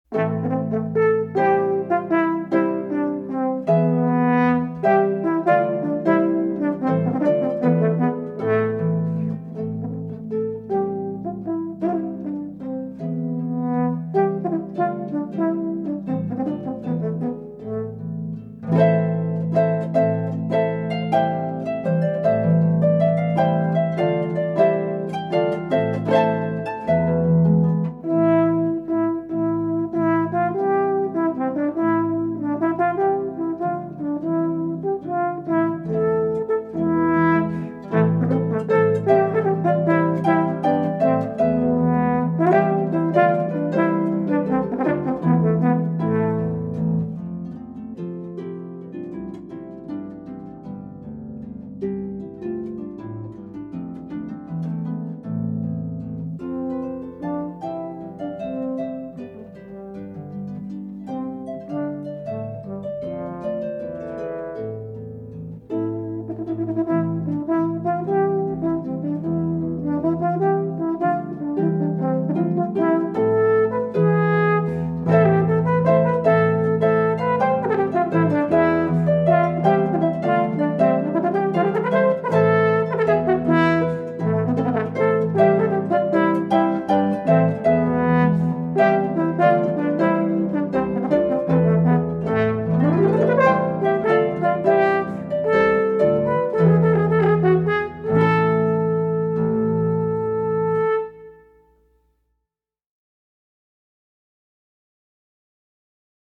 for harp and horn